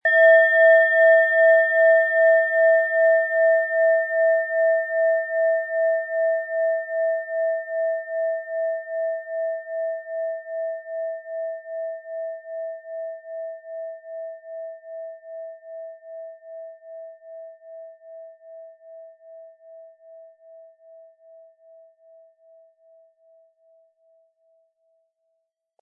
Planetenschale® Freudig sein & Wohlige Energie im Brustbereich mit Hopi-Herzton, Ø 9,9 cm, 260-320 Gramm inkl. Klöppel
Hopi Herzton
Um den Original-Klang genau dieser Schale zu hören, lassen Sie bitte den hinterlegten Sound abspielen.
Im Preis enthalten ist ein passender Klöppel, der die Töne der Schale schön zum Schwingen bringt.
HerstellungIn Handarbeit getrieben
MaterialBronze